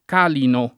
[ k # lino ]